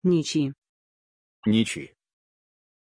Aussprache von Nici
pronunciation-nici-ru.mp3